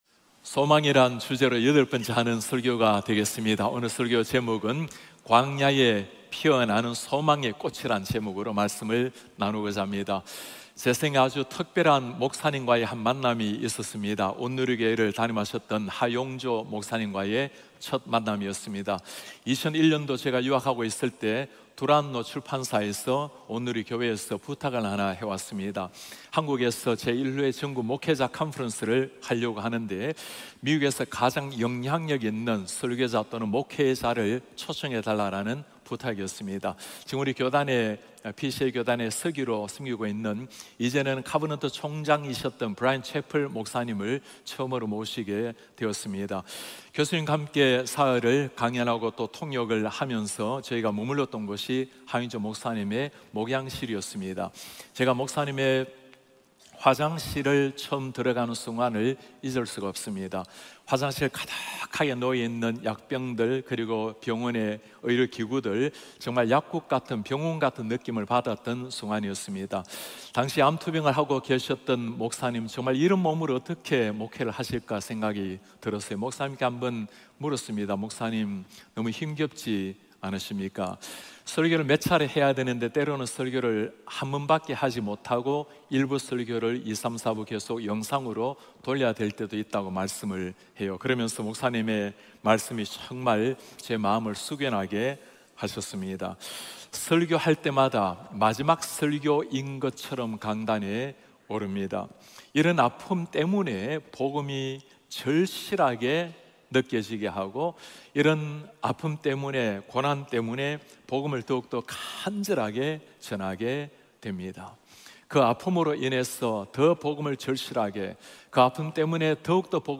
예배: 주일 예배